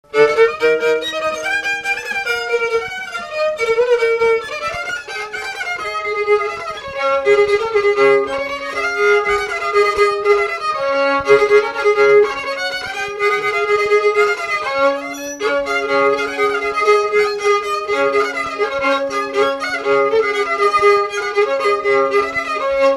Mémoires et Patrimoines vivants - RaddO est une base de données d'archives iconographiques et sonores.
Polka
Résumé Instrumental
danse : polka
Catégorie Pièce musicale inédite